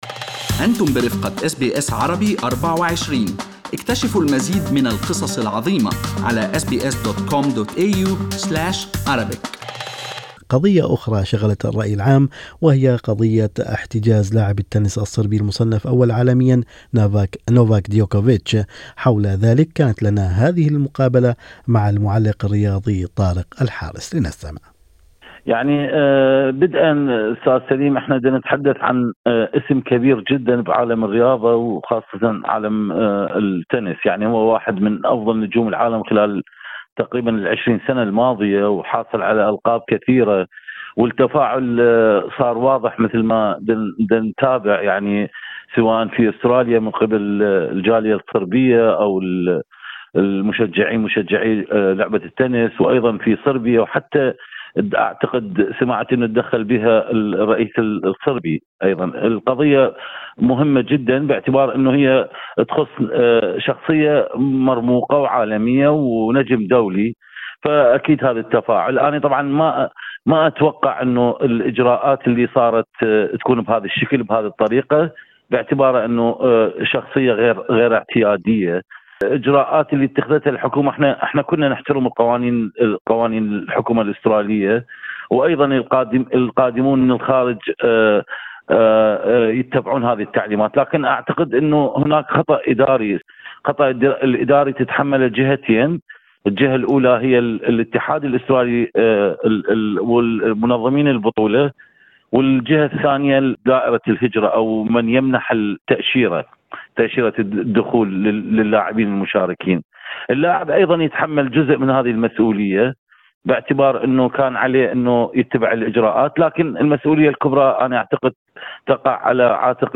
لسماع لقاء المعلق الرياضي حول القضية يرجى الضغط على الرابط الصوتي المرفق بالصورة أعلاه. قالت الحكومة الأسترالية الأحد إن الصربي نوفاك ديوكوفيتش المصنف أول عالميًا لم يتلق اللقاح المضاد لفيروس كوفيد-19، وإنه يجب رفض معركته القانونية للبقاء في البلاد والمشاركة في بطولة أستراليا المفتوحة لكرة المضرب.